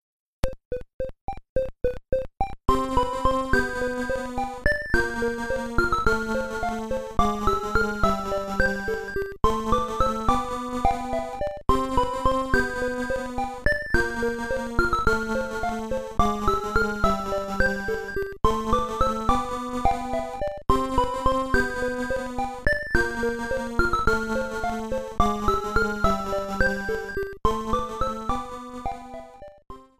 The opening theme